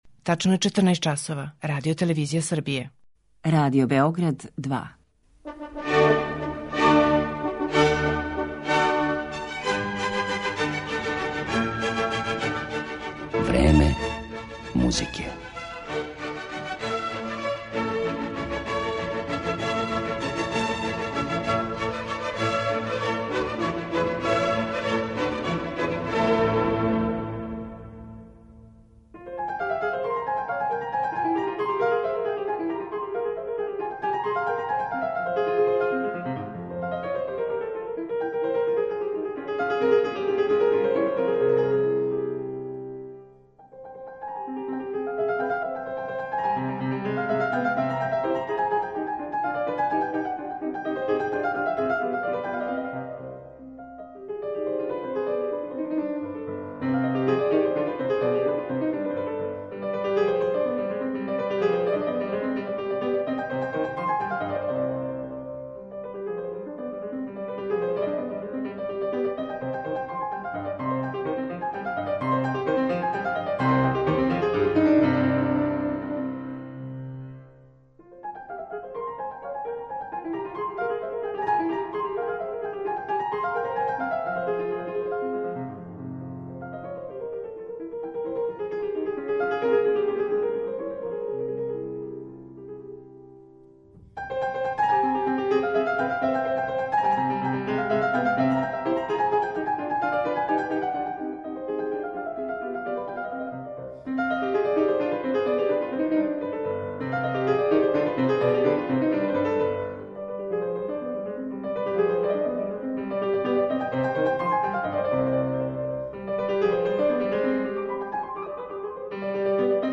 Јевгениј Судбин, пијаниста